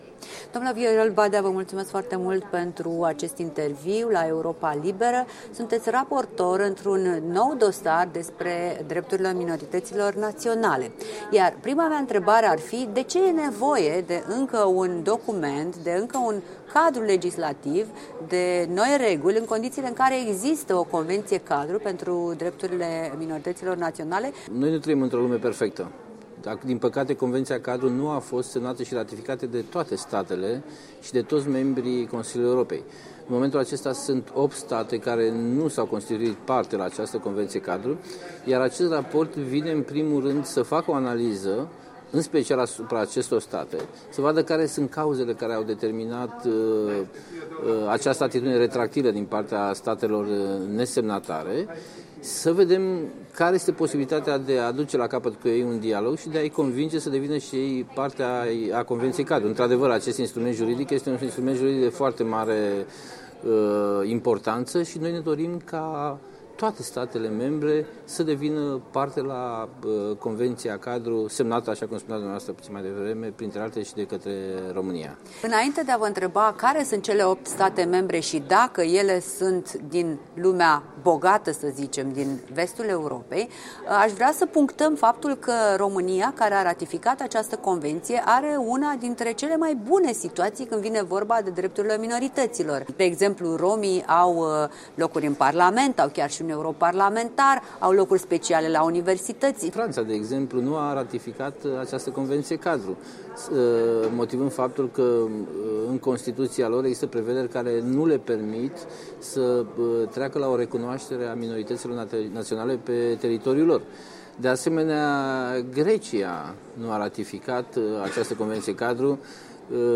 Interviu cu senatorul pentru românii din diaspora în Parlamentul de la București și membru al Adunării Parlamentare a Consiliului Europei.
Într-un interviu acordat la Strasbourg, în marja sesiunii Adunării Parlamentare a Consiliului Europei, Badea, care este și autorul unui raport APCE despre minoritățile naționale, explică de ce anumite state sunt reticente în a adera la Carta pentru protecția minorităților naționale, pe care atât România, cât și Moldova s-au grăbit să o ratifice imediat după publicare.
Interviu cu senatorul român Viorel Badea